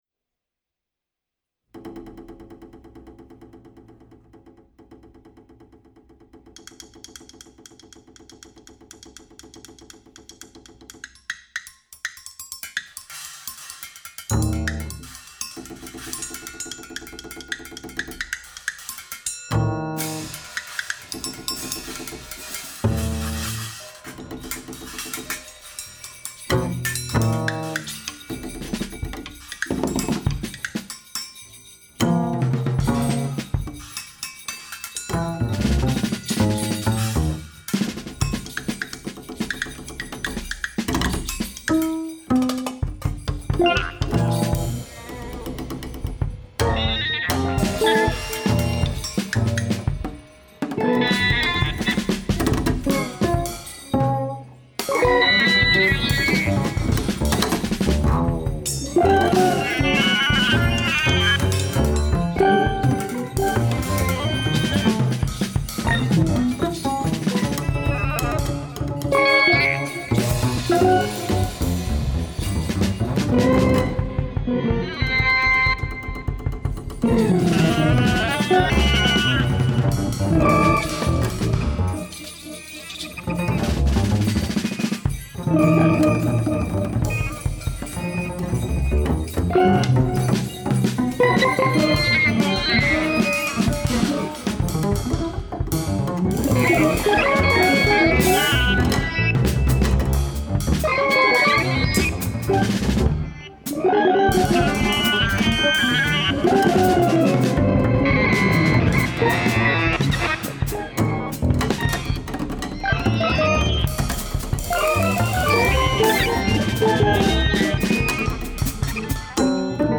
guitar & effects
double bass
drums & percussion